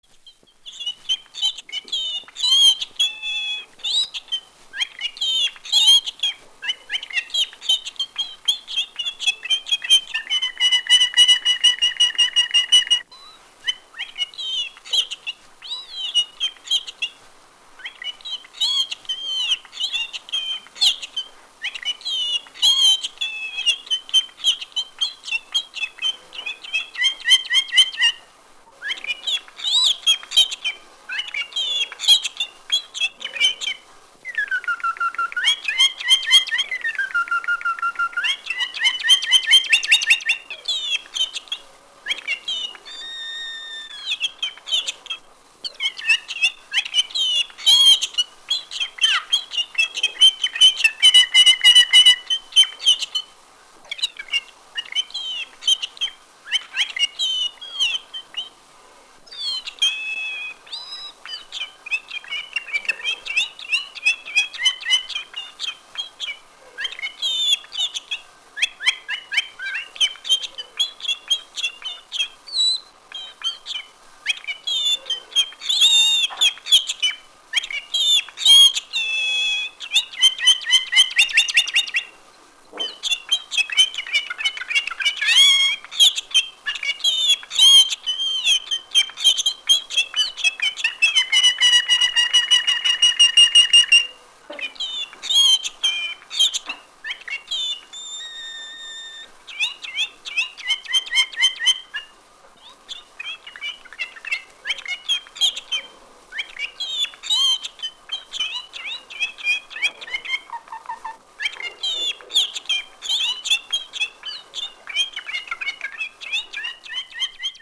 Пение канарейки
Ее задорные мелодии подарят позитив и поднимут настроение.
Канарейка Пинчи:
kanarejka-pinchi.mp3